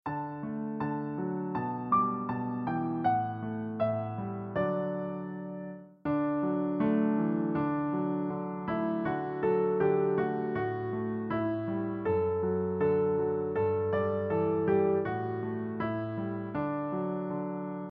Sheet Music — Piano Solo Download
Downloadable Instrumental Track